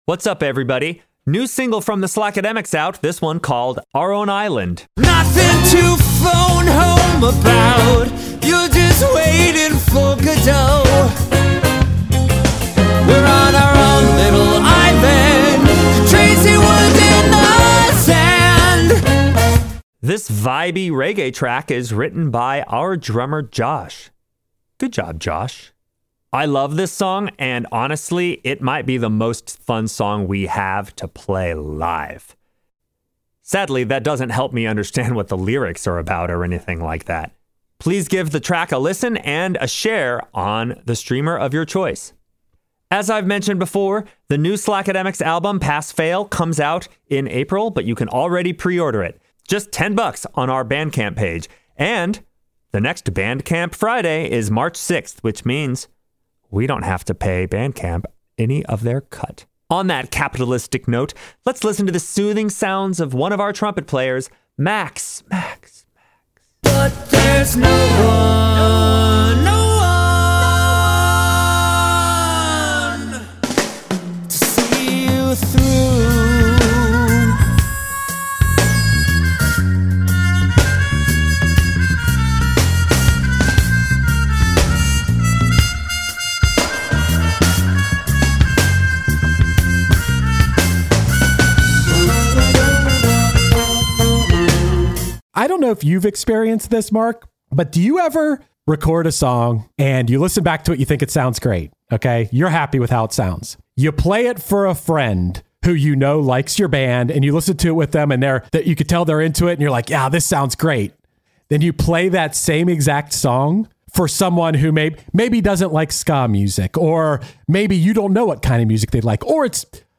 This and more in a fun conversation that would just barely fit on a CD.